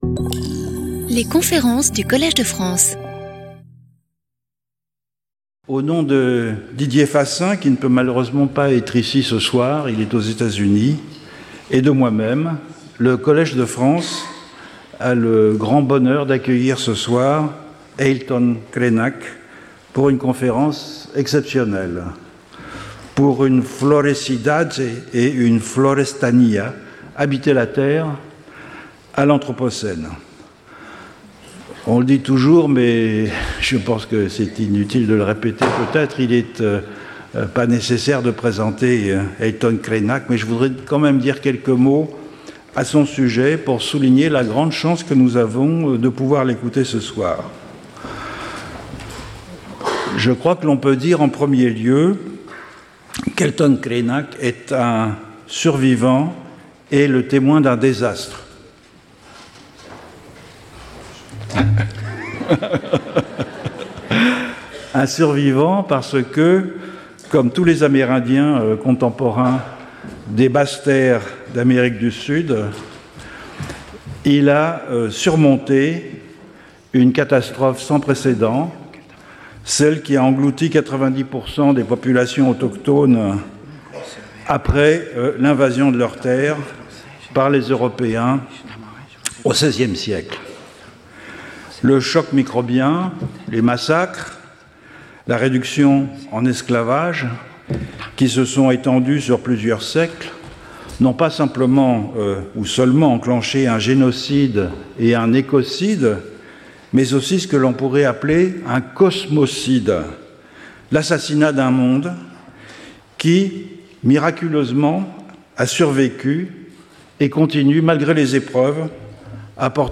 Skip youtube video player Listen to audio Download audio Audio recording French version of the video. Other versions of the conference Watch the video in its original version (Portuguese and French) Watch the video in Portuguese Abstract I'd like to talk about metropolises and their civilizational effects from the perspective of an Amerindian who lives on the banks of the Rio Doce river, which was hit in November 2015 by a toxic mudflow caused by mining.